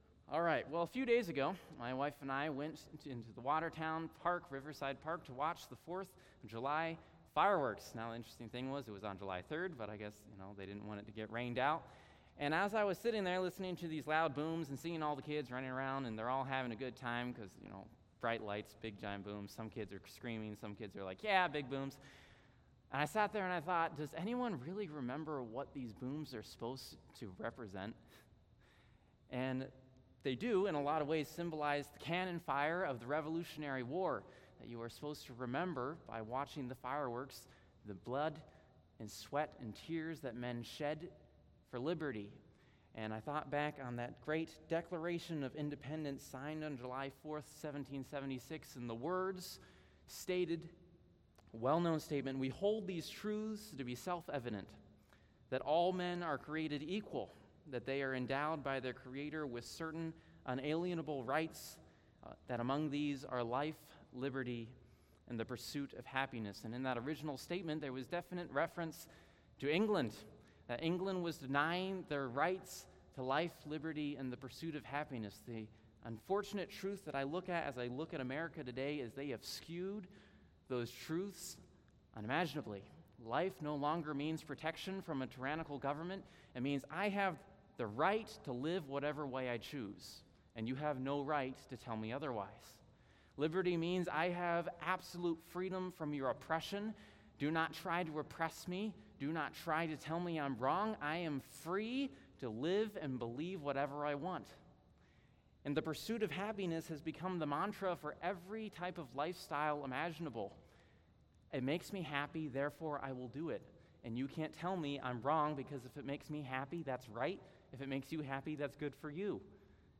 Psalm 1 Service Type: Sunday Evening What is happiness?